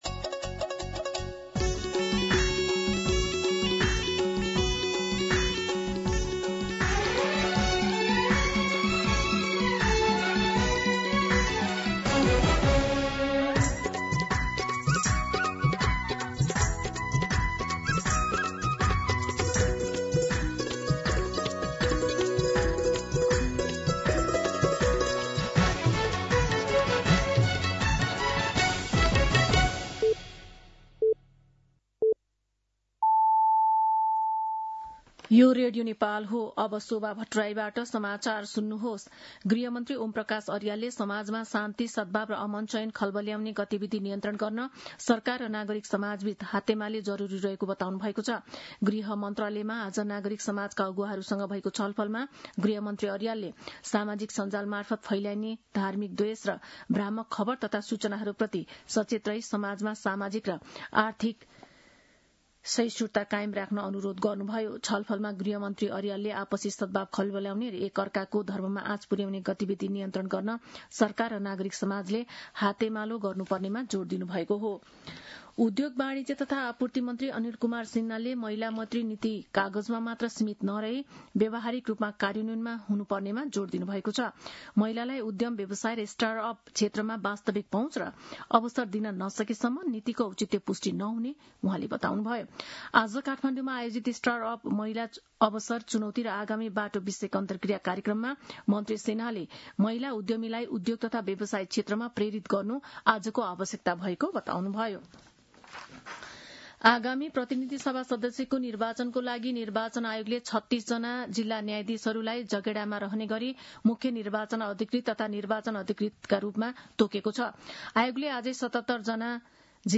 दिउँसो १ बजेको नेपाली समाचार : २१ पुष , २०८२